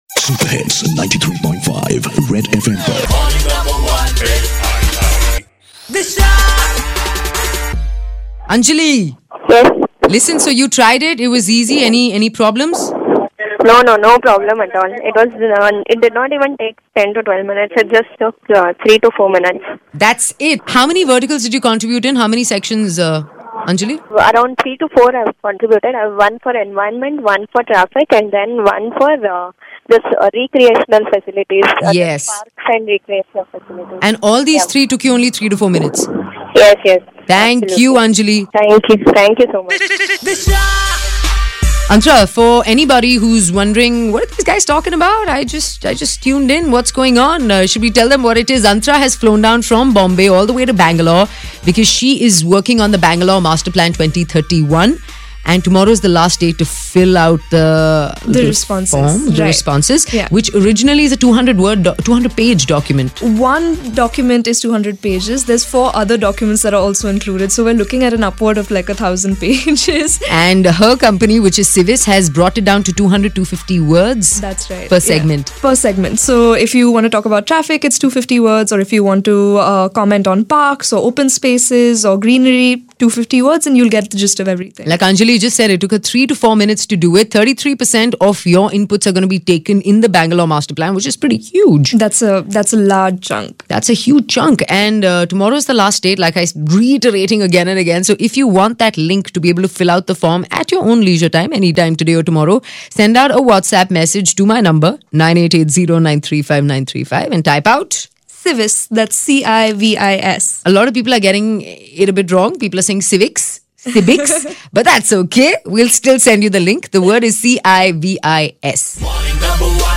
Listener giving feedback about the civis plan form